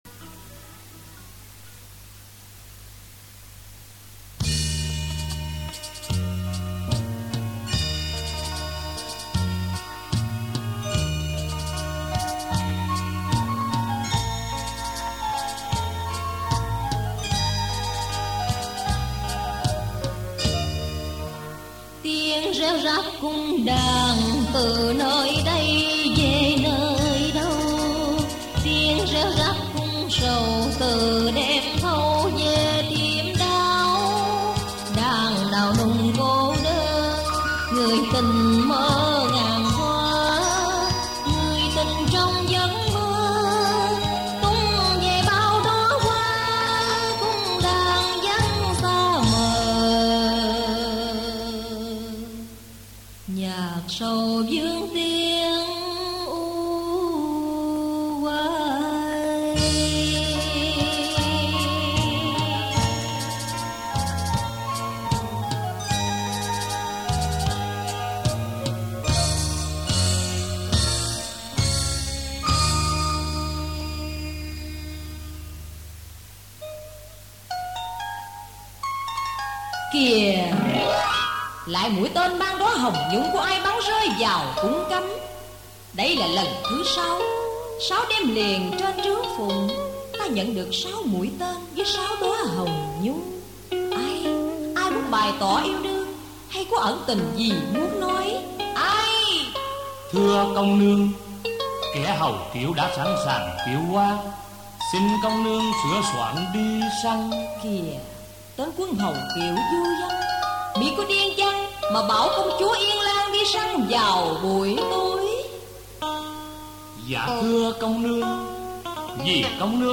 Thể loại: Cải Lương